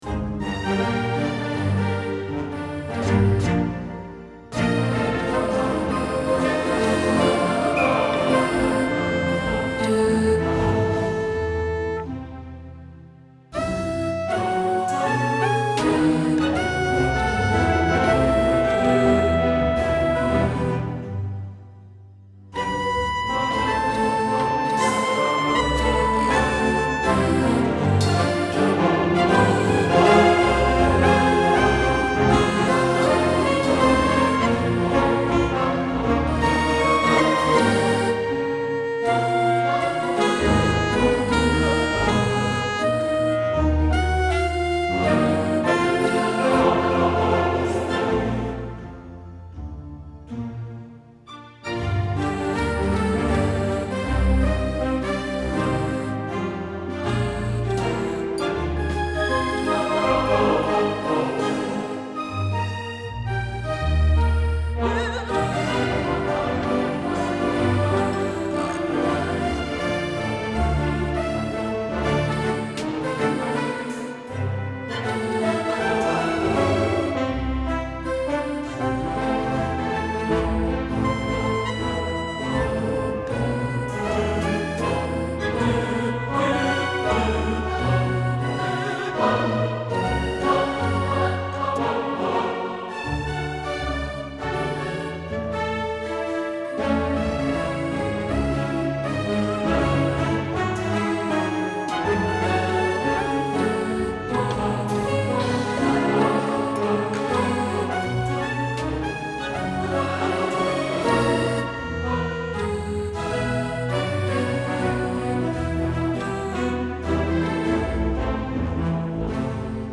Orchestra & Combo